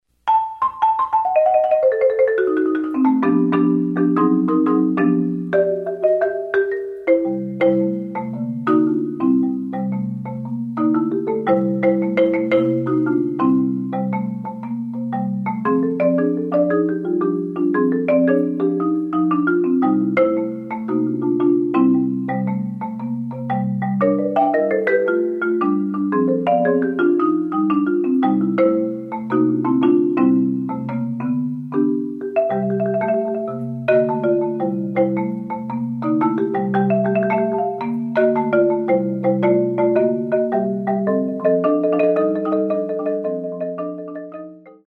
Genre: Marimba (4-mallet)
Solo Marimba (4-octave)